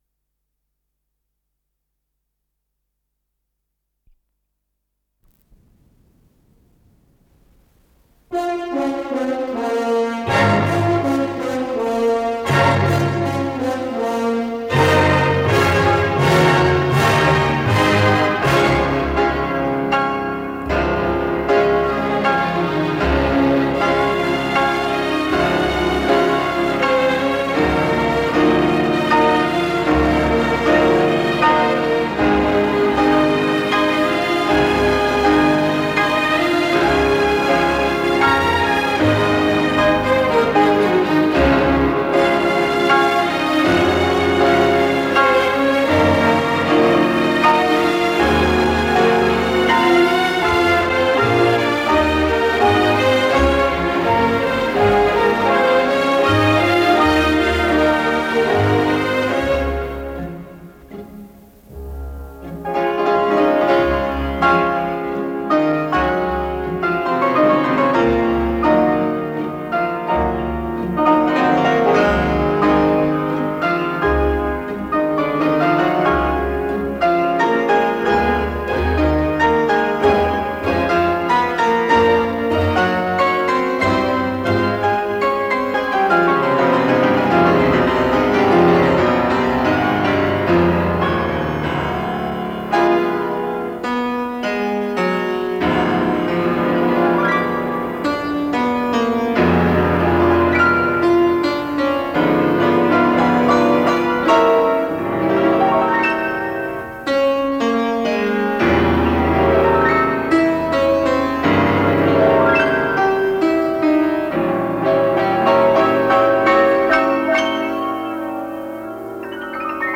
Исполнитель: Эмиль Гилельс - фортепиано
для фортепиано с оркестром
Си бемоль мажор